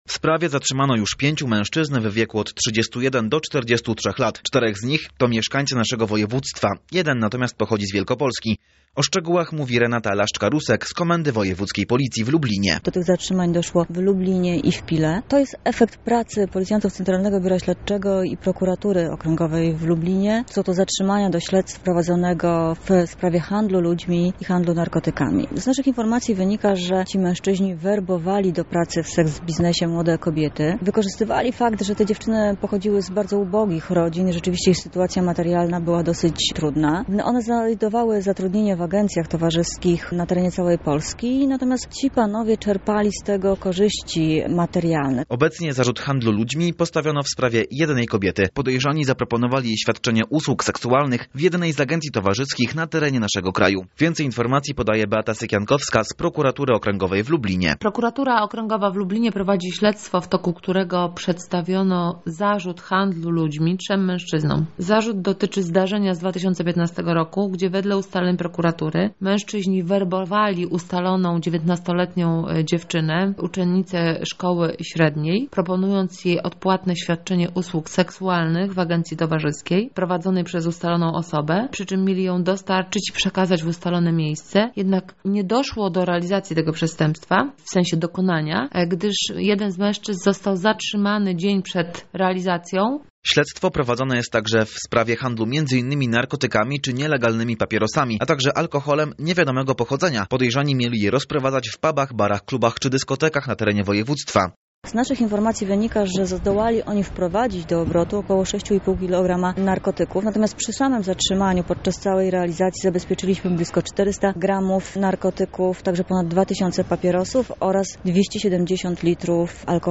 Szczegóły sprawy poznał nasz reporter.